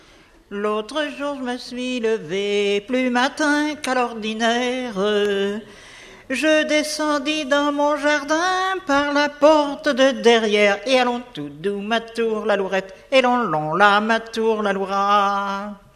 chanteur(s), chant, chanson, chansonnette